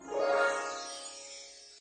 magic_harp_2.ogg